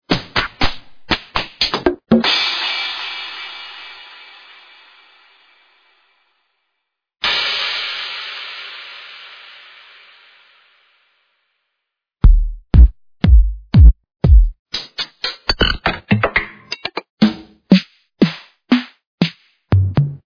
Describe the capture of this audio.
(the prelisten files are in a lower quality than the actual packs)